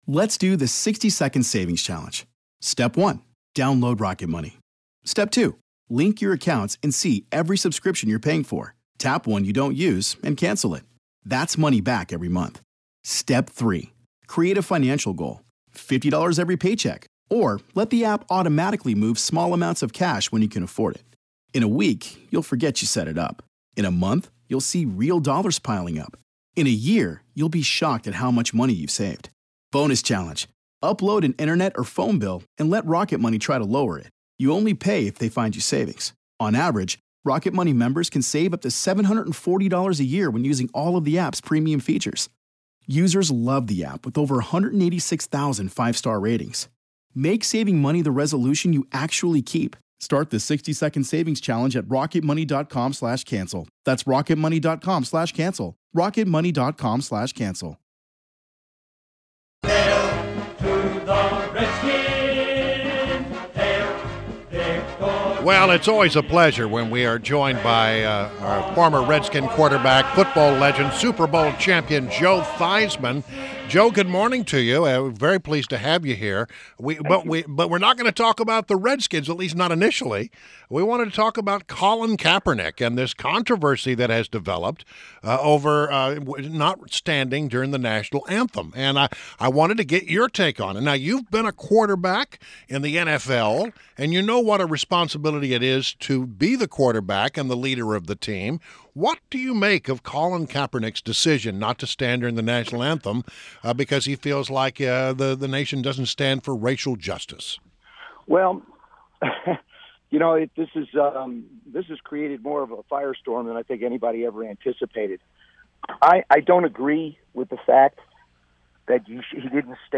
WMAL Interview - JOE THEISMANN - 08.30.16